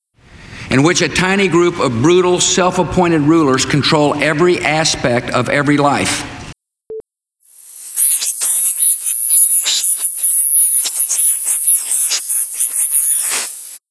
On 11KHz audio, inverting the spectrum does not render voice totally scrambled